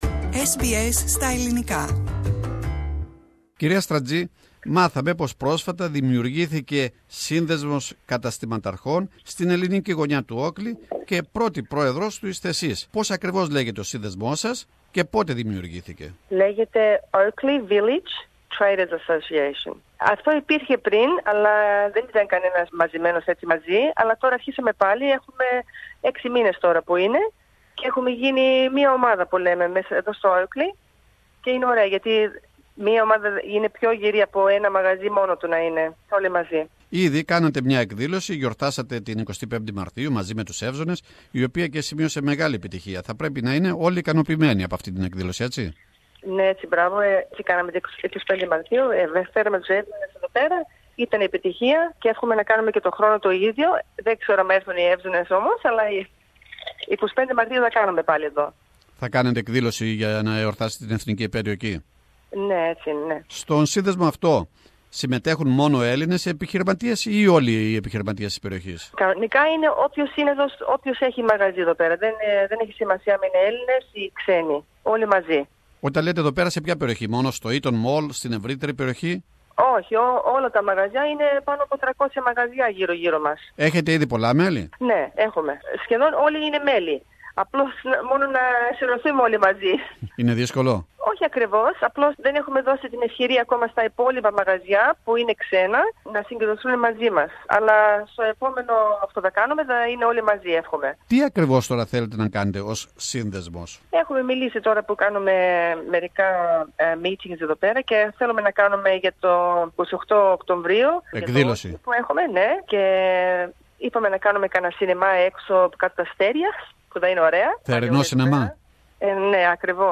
The Oakleigh Business and Traders Association on SBS Greek.